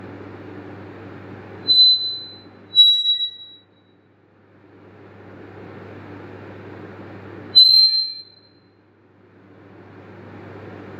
Corpo, cassa, microfono.
Effetto Larsen.
Body, speaker, microphone.
Larsen effect.
Performance, 2022.